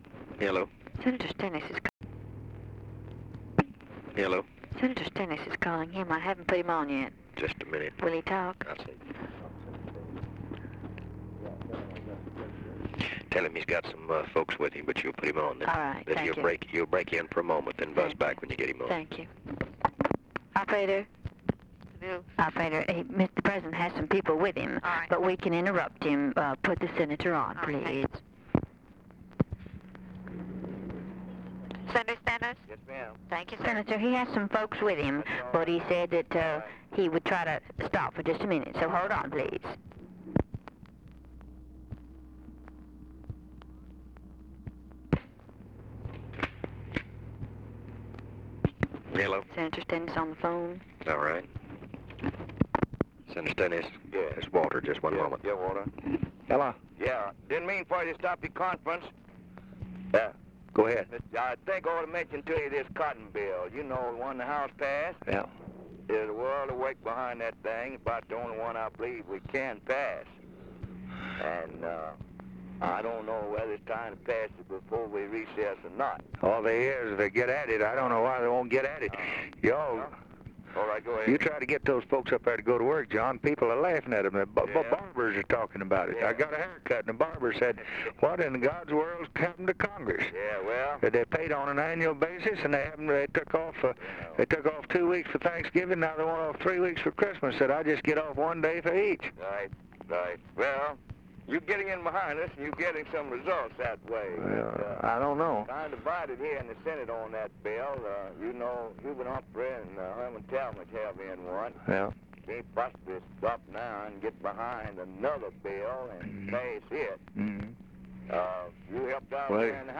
Conversation with JOHN STENNIS, December 6, 1963